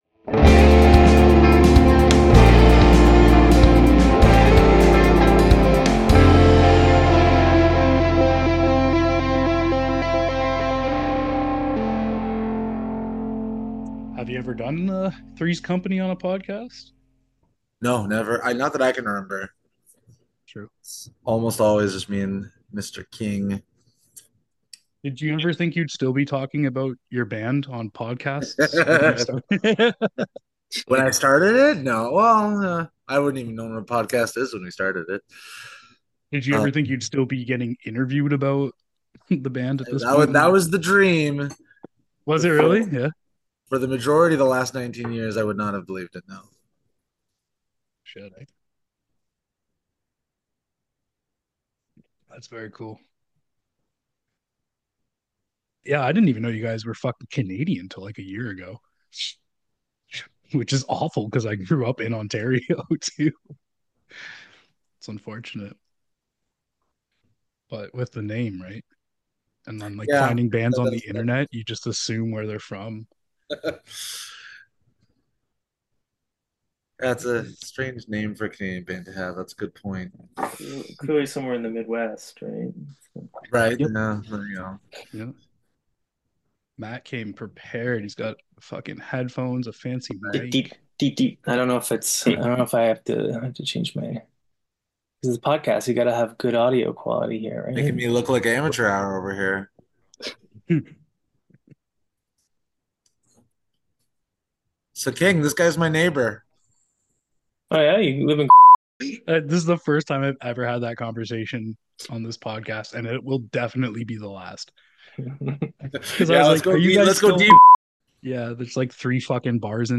A punk show interviewing artists and labels that produce various music genres.